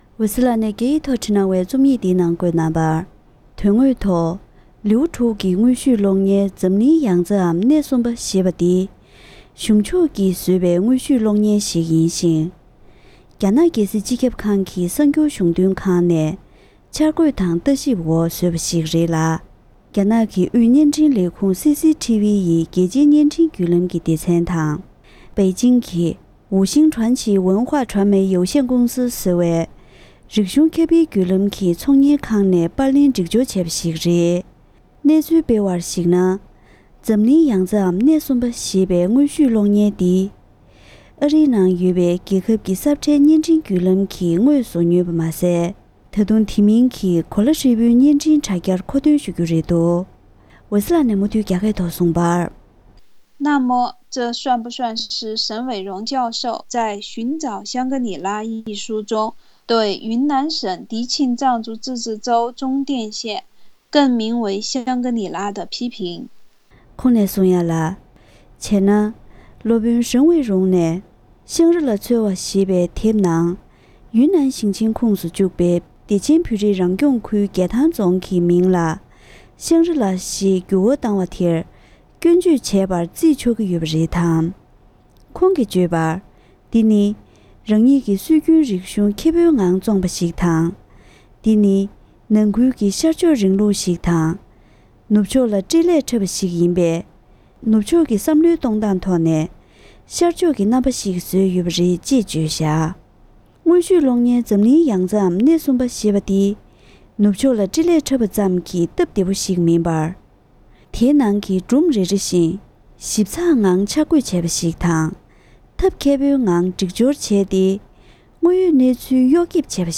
ཡིད་དབང་འཕྲོག་པའི་བོད་ཀྱི་དྲིལ་བསྒྲགས། ལེ་ཚན་གཉིས་པ། སྒྲ་ལྡན་གསར་འགྱུར།